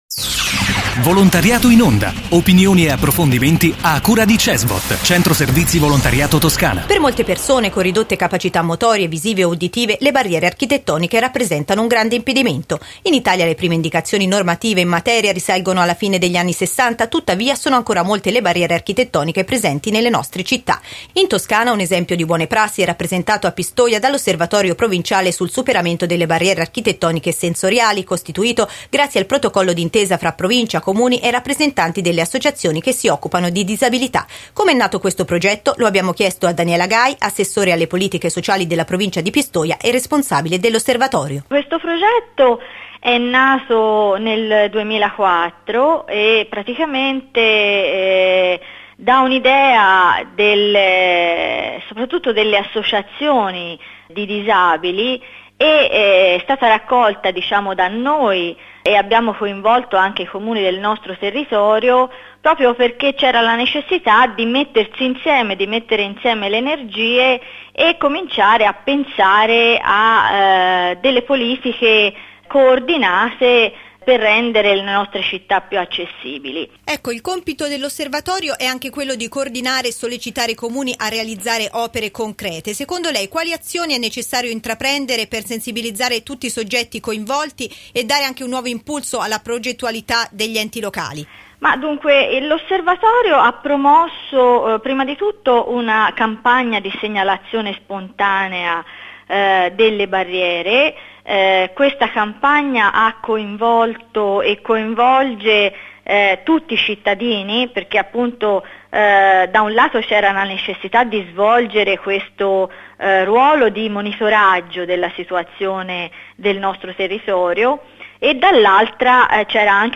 Intervista a Daniela Gai, assessora alle politiche sociali della Provincia di Pistoia e responsabile dell'Osservatorio provinciale sul superamento delle barriere architettoniche e sensoriali